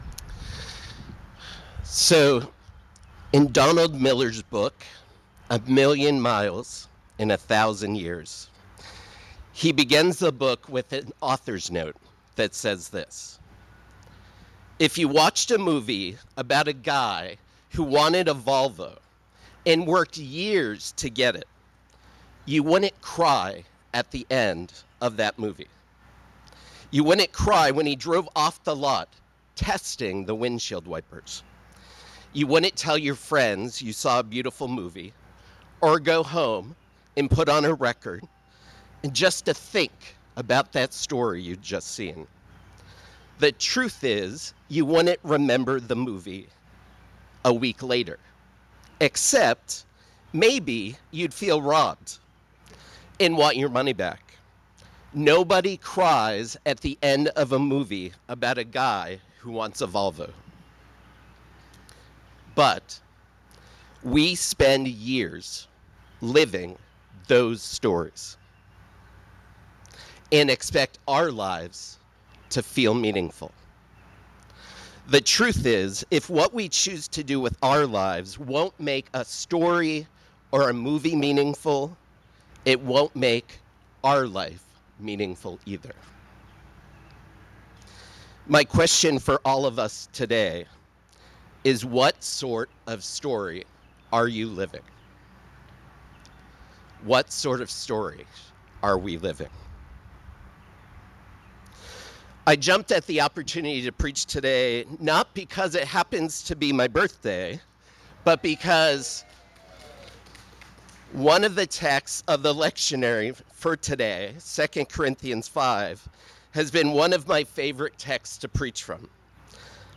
A message from the series "Ordinary Time."